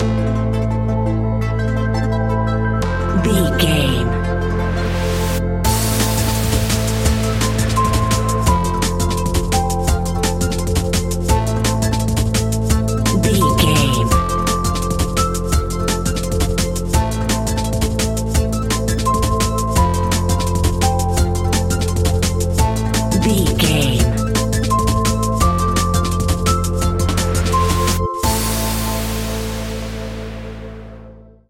Aeolian/Minor
Fast
aggressive
groovy
futuristic
industrial
frantic
drum machine
synthesiser
electronic
sub bass
synth leads